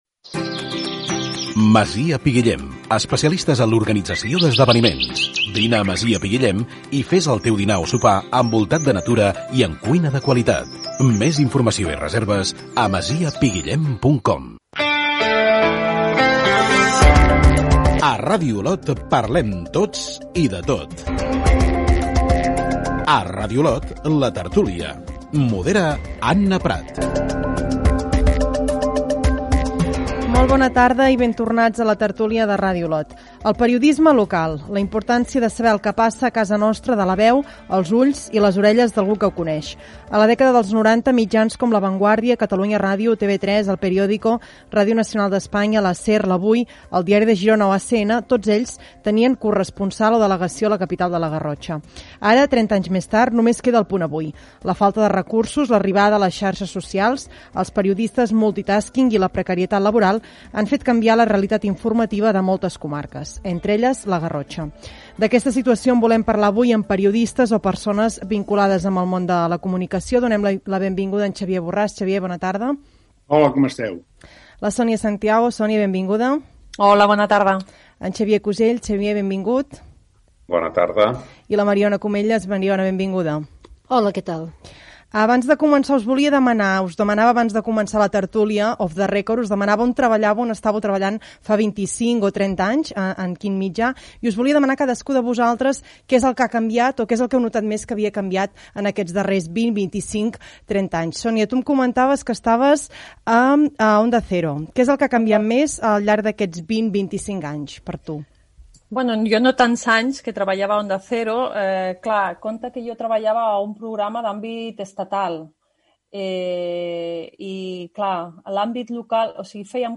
9b071eadef0964ab50d227c35cf3a87965ef8244.mp3 Títol Ràdio Olot Emissora Ràdio Olot Titularitat Privada local Nom programa La tertúlia Descripció Publicitat, careta del programa, tertúlia dedicada a la davallada en la presència dels mitjans de comunicació tradicionals al territori.